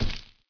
snd_splat.ogg